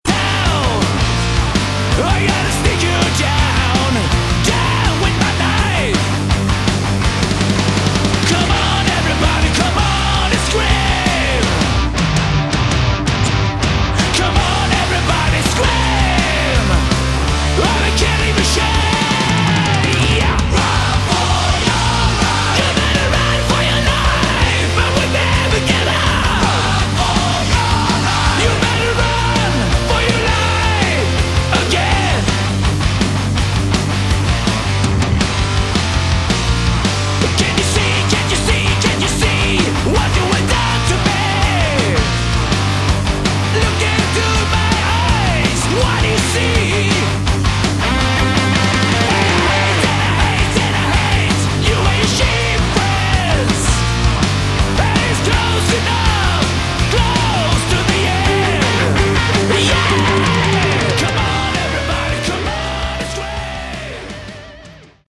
Category: Hard Rock
lead vocals, rhythm guitar
bass, backing vocals
drums, backing vocals
lead guitar, backing vocals